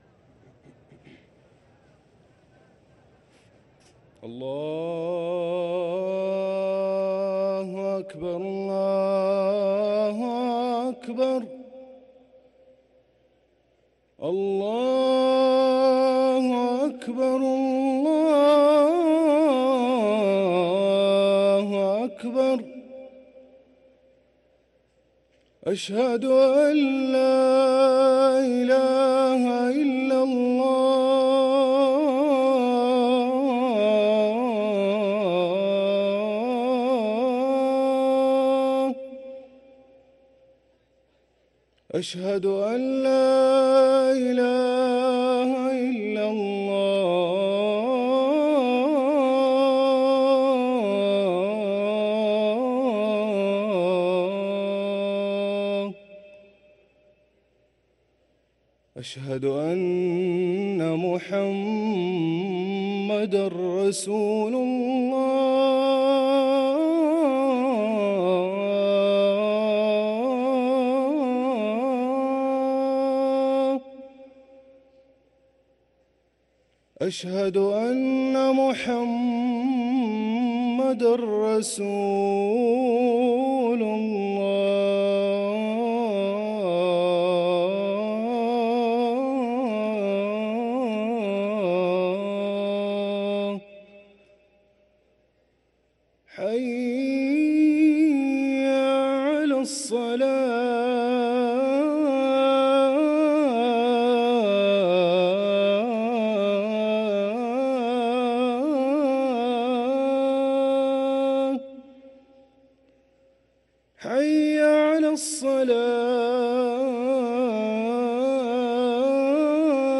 أذان الجمعة الأول